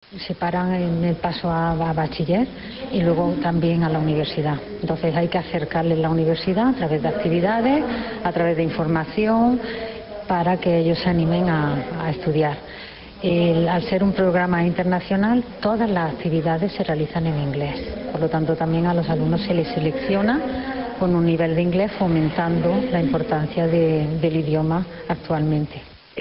explicó en dicho acto formato MP3 audio(0,60 MB) que las prevenciones y prejuicios llevan a muchos alumnos a renunciar a la formación universitaria, y que este proyecto trata precisamente de combatir esta tendencia, mostrando a los preuniversitarios con discapacidad sus posibilidades de integración y promoviendo su movilidad por Europa, fomentando al tiempo la realización de actividades en inglés, como se hace también en los campus de Amberes y Lisboa.